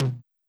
CLF Tom 1.wav